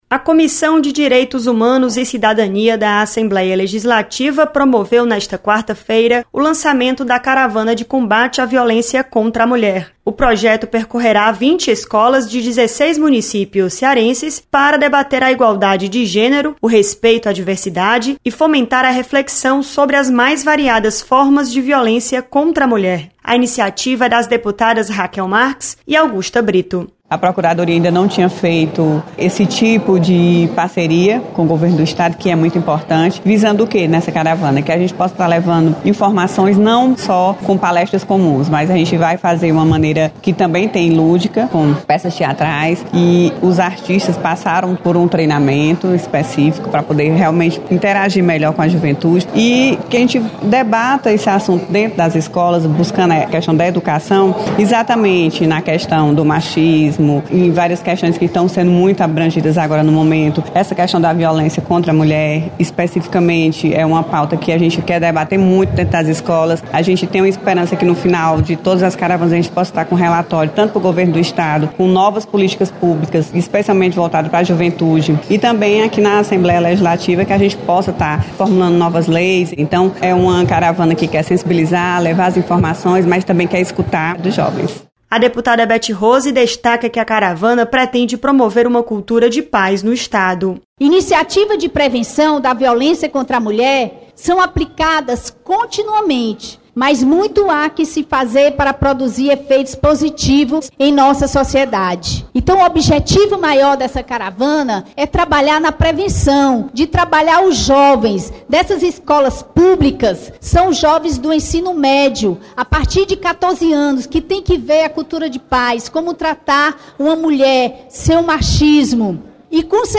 Você está aqui: Início Comunicação Rádio FM Assembleia Notícias Lançamento